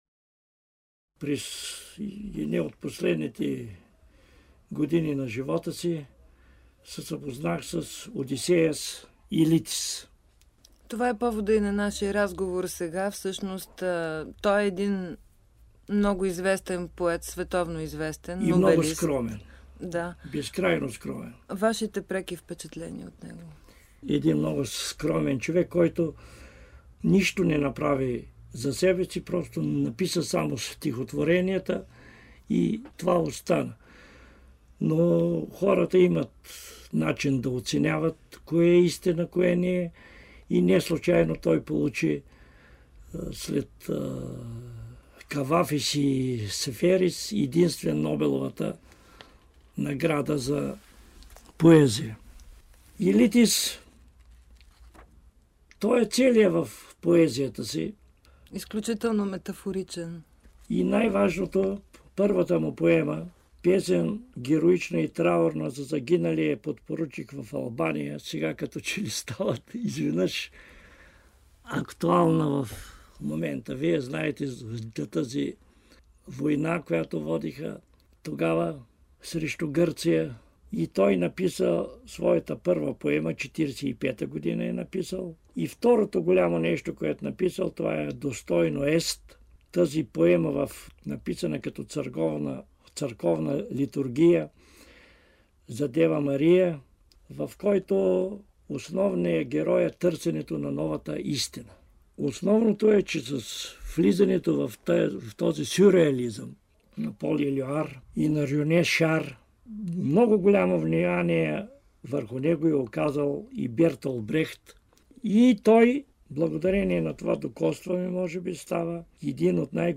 За запознанството си с носителя на Нобеловата награда за литература за 1979 година Одисеас Елитис Божидар Божилов говори в свое интервю за БНР през пролетта на 1997 година.